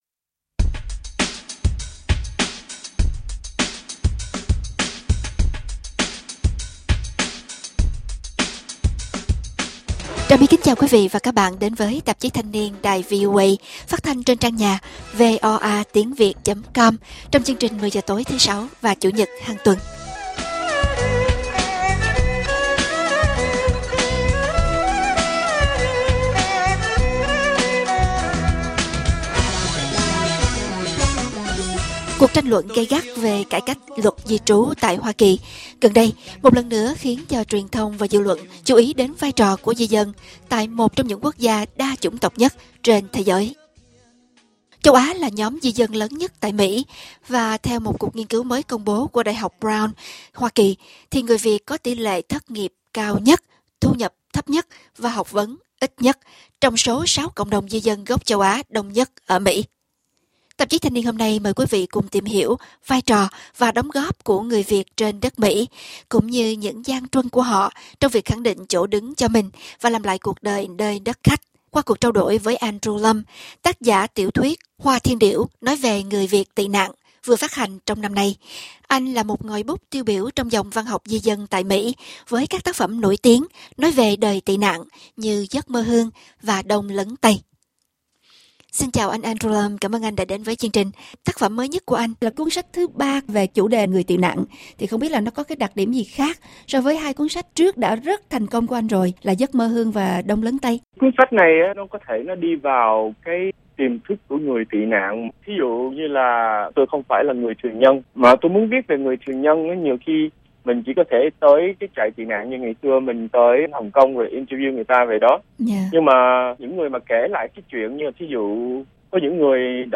Phỏng vấn